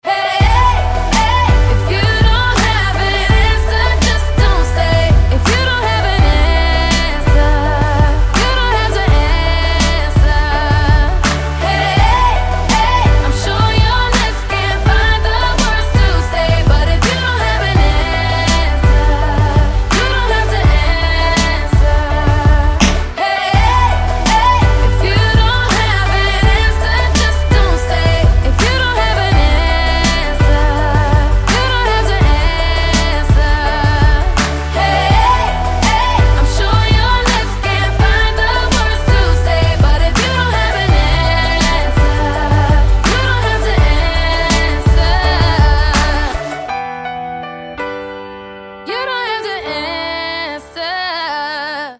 • Качество: 320, Stereo
поп
медленные
RnB
красивый женский голос
медляк